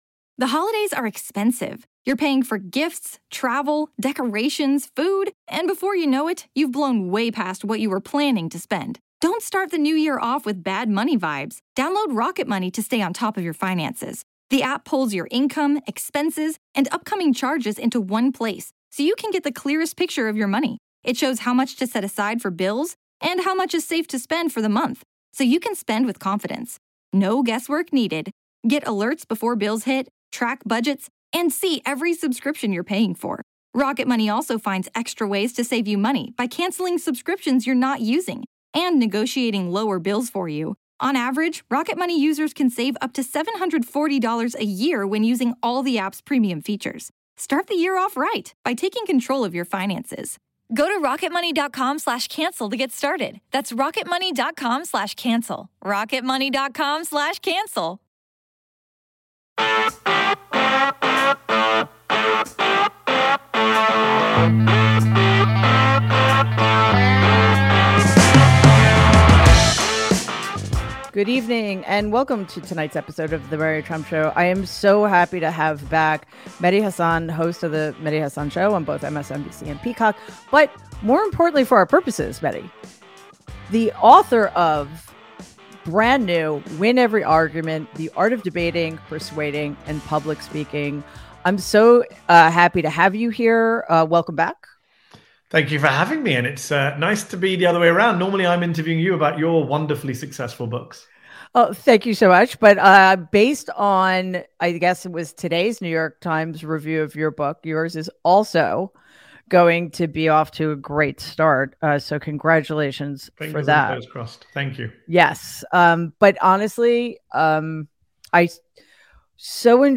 Mary Trump welcomes author and journalist Mehdi Hasan to discuss the principles of debate, the moves you can deploy to defeat an adversary and convince an audience, and the best ways to make winning arguments against the radical Right.